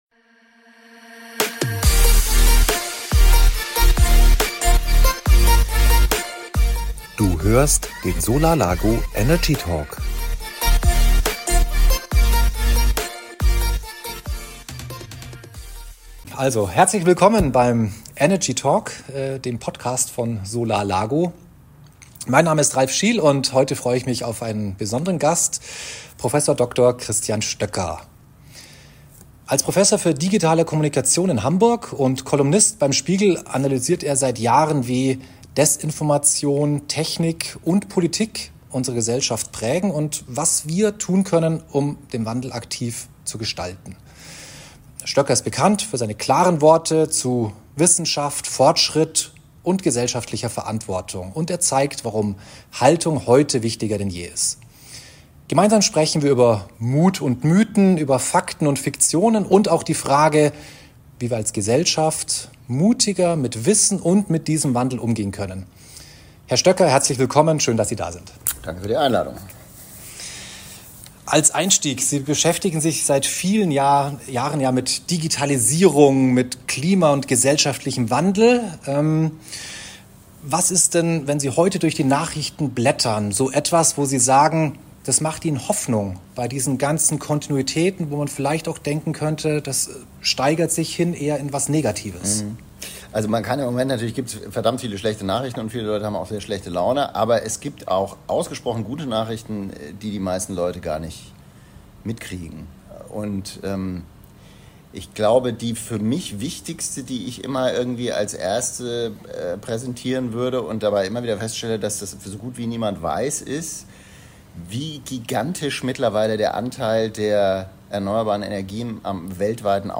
Christian Stöcker im Gespräch über Energiewende, Klimamythen, Desinformation und aktive Hoffnung – faktenbasiert, differenziert, ermutigend.